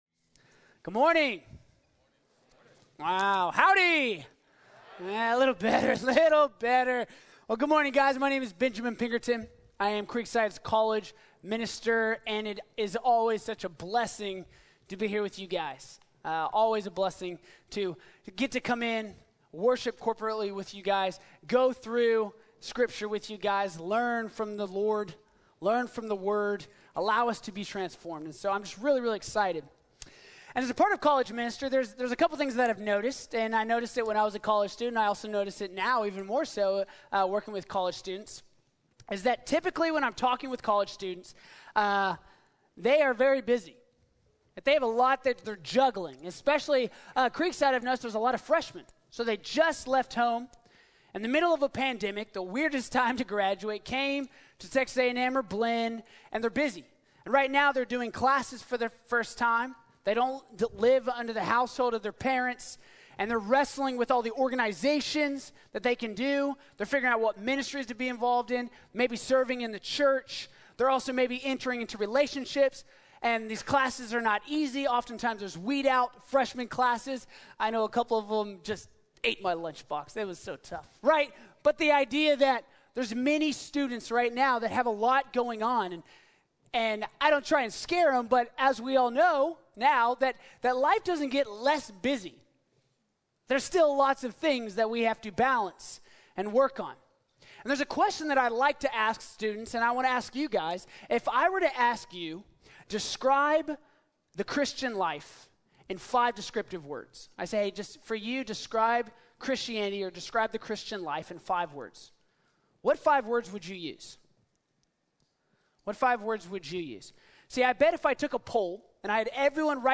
La verdadera libertad | Sermón | Iglesia Bíblica de la Gracia